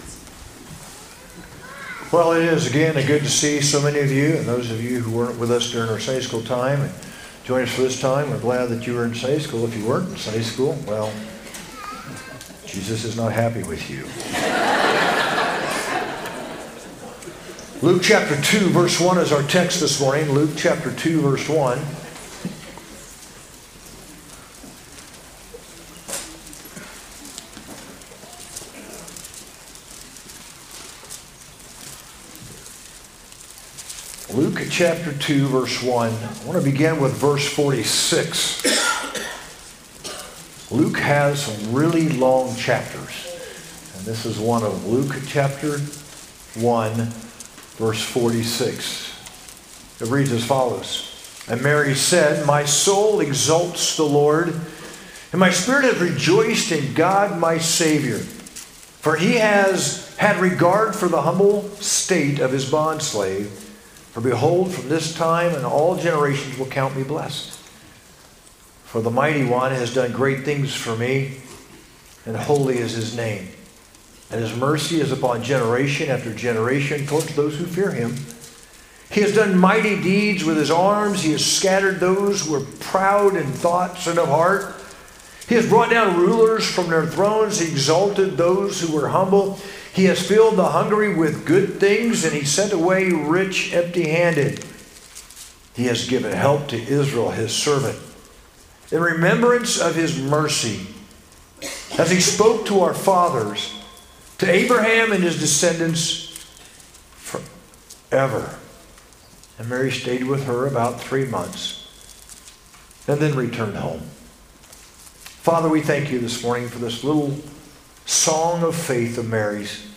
Sunday Morning | Christmas Series